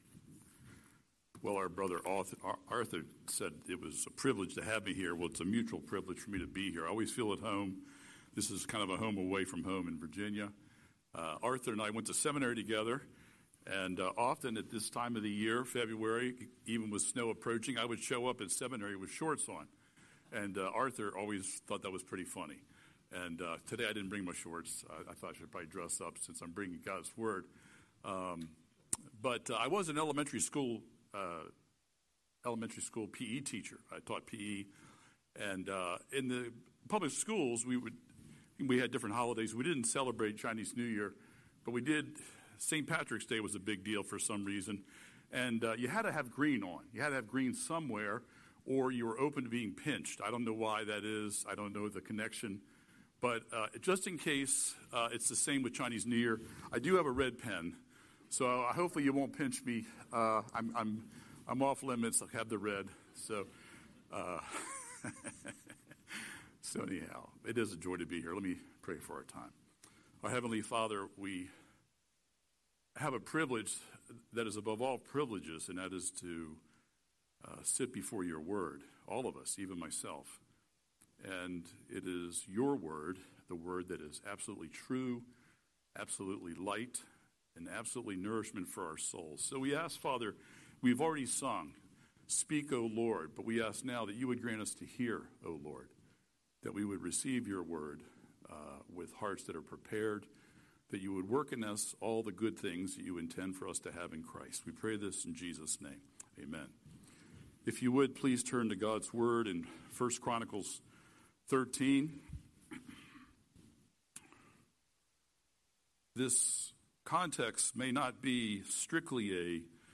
Scripture: 1 Chronicles 13:1–14 Series: Sunday Sermon